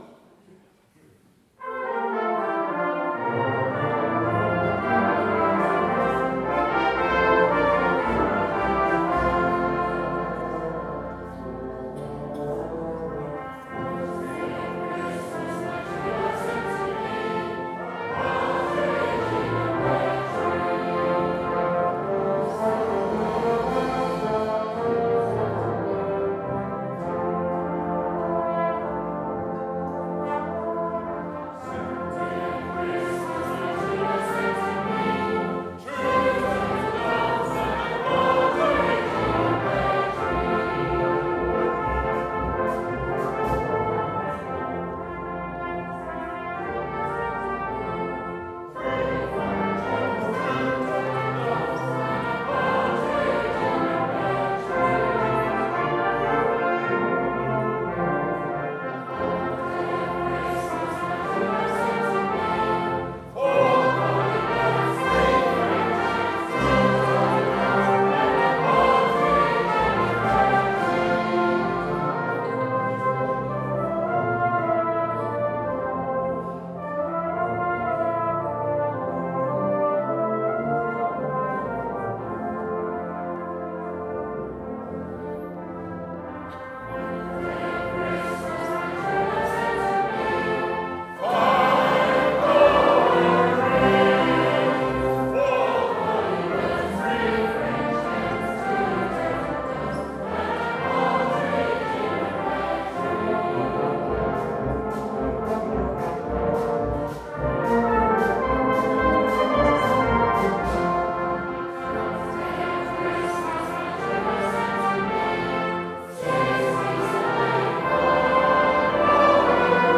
Somersham Carols by Candlelight Christmas Concert 2019 - Somersham Town Band
These recordings were taking during the 'live' performance and the Microphones were set close to the audience. Because of this you will hear a little background noise and some very enthusiastic audience participation!